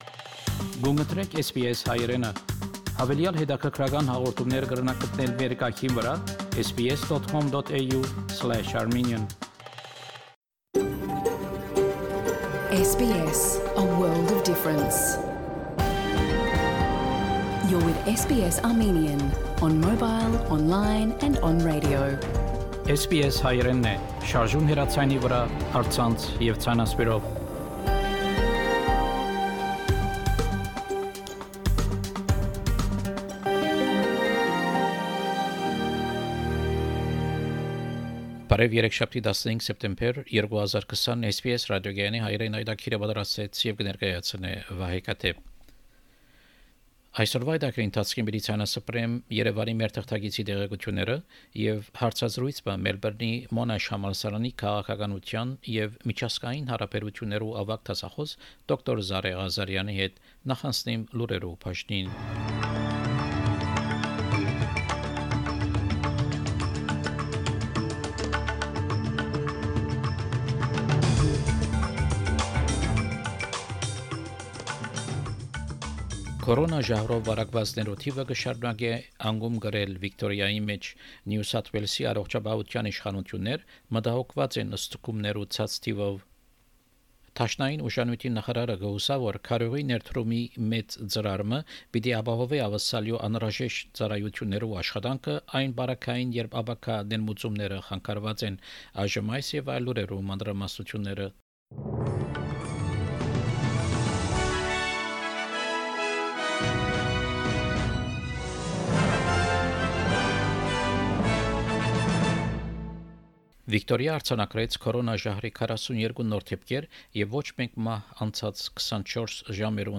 SBS Armenian news bulletin – 15 September 2020
SBS Armenian news bulletin from September 15, 2020 program.